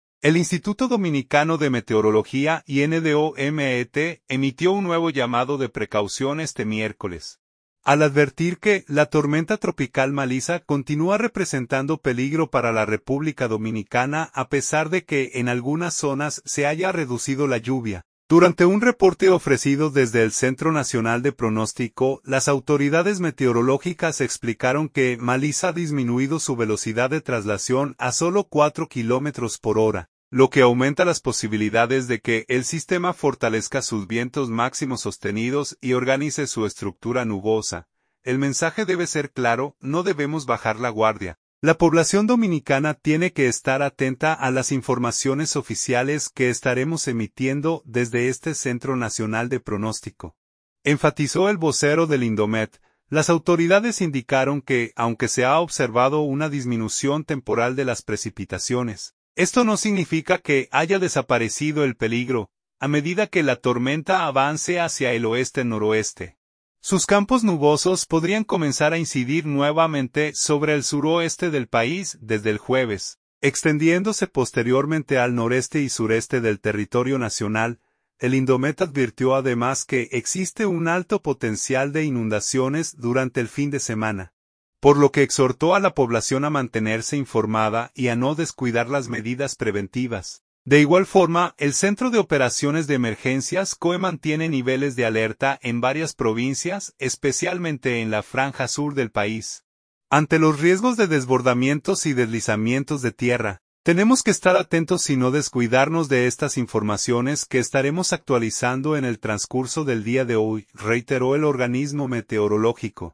Durante un reporte ofrecido desde el Centro Nacional de Pronóstico, las autoridades meteorológicas explicaron que Melissa ha disminuido su velocidad de traslación a solo 4 kilómetros por hora, lo que aumenta las posibilidades de que el sistema fortalezca sus vientos máximos sostenidos y organice su estructura nubosa.